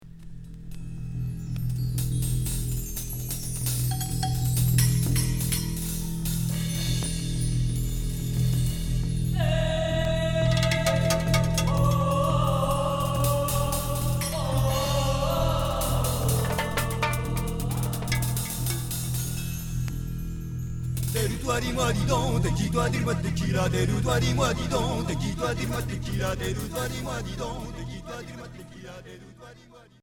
Folk rock